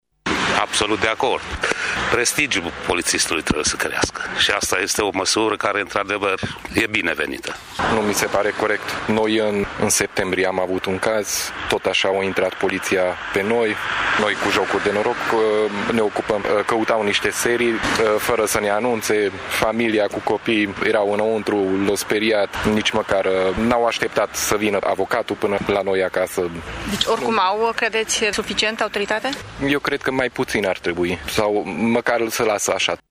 Mulți târgumureșeni își doresc ca autoritatea polițistului să crească, mai puțin cei care sunt în vizorul acestora: